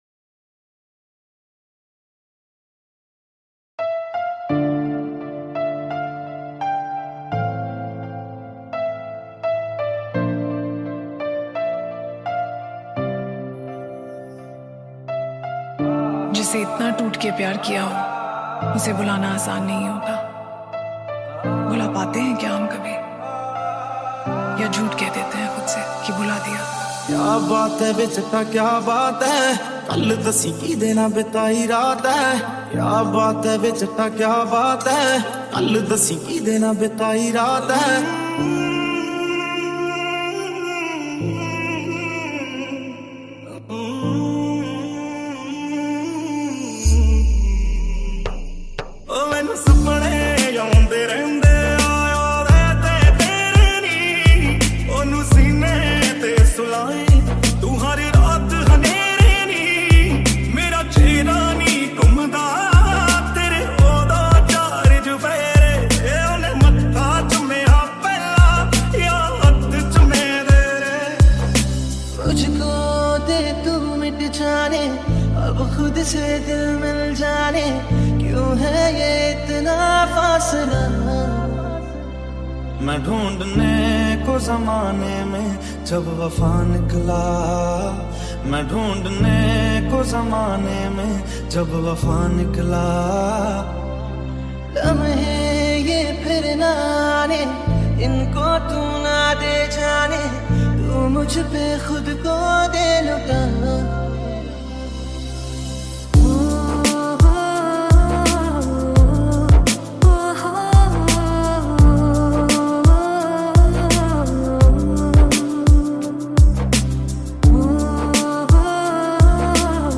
High quality Sri Lankan remix MP3 (3.9).
remix